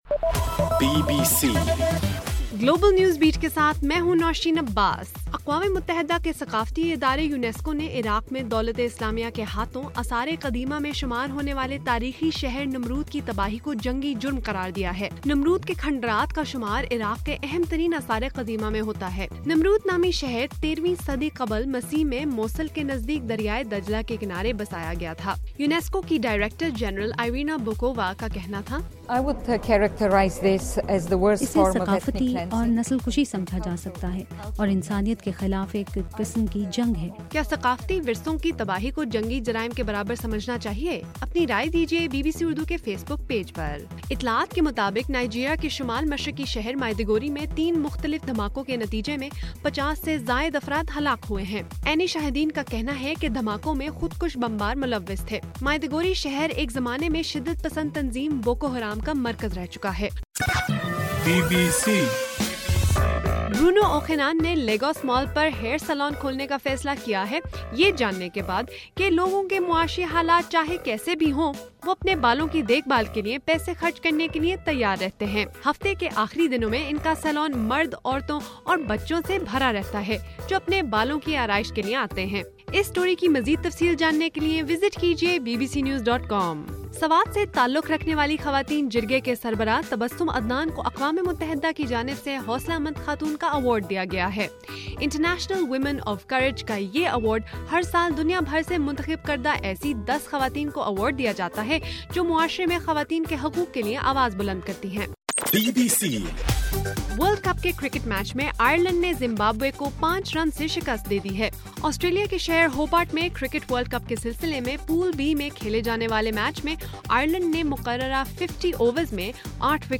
مارچ 7: رات 12 بجے کا گلوبل نیوز بیٹ بُلیٹن